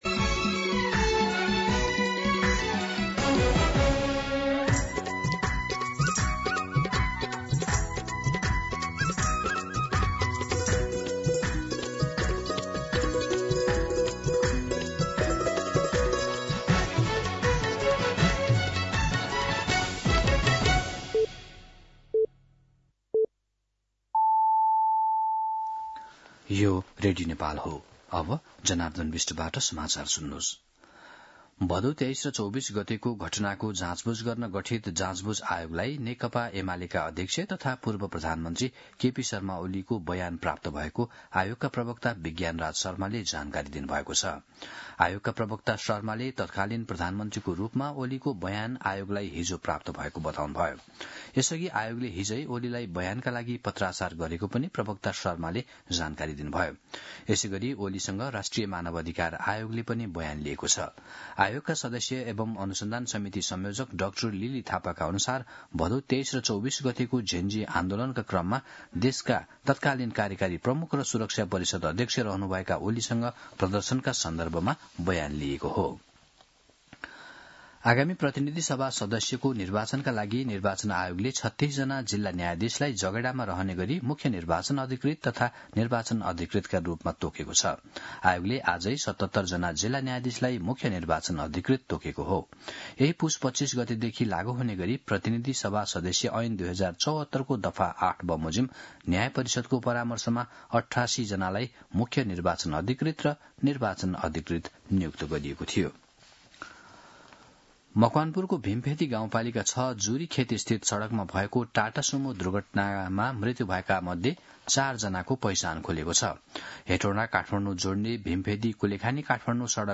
मध्यान्ह १२ बजेको नेपाली समाचार : २१ पुष , २०८२